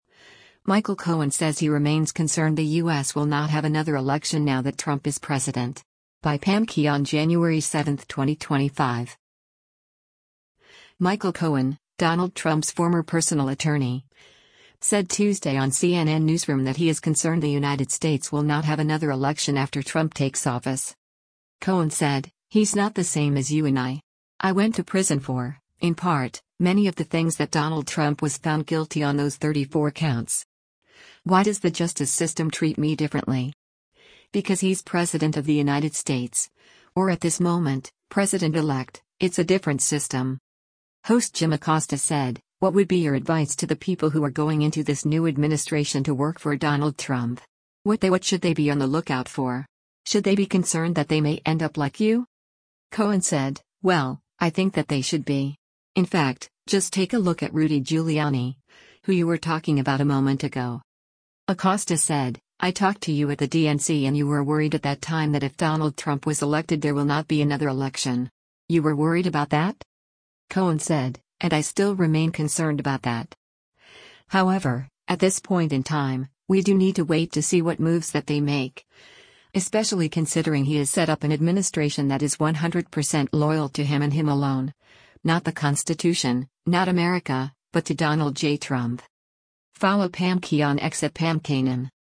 Michael Cohen, Donald Trump’s former personal attorney, said Tuesday on CNN Newsroom that he is “concerned” the United States will not have another election after Trump takes office.